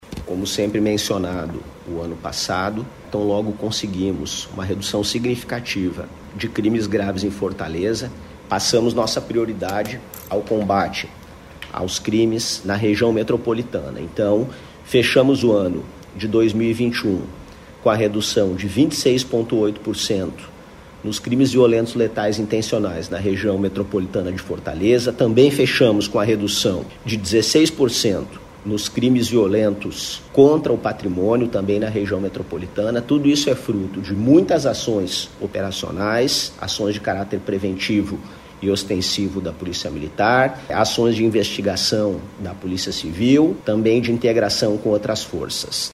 O secretário da SSPDS, Sandro Caron, destaca a integração entre as Polícias cearenses e o trabalho de inteligência como fatores determinantes para a retração.